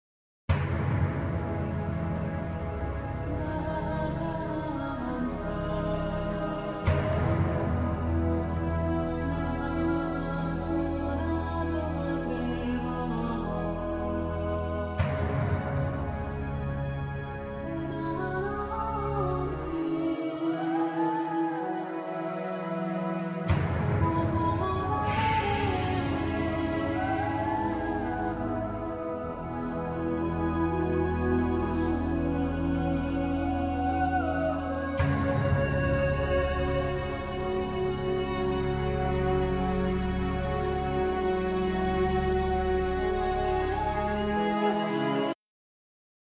Keyboards
Voice
Drum programming
Guitar
Bass
Piano
Violin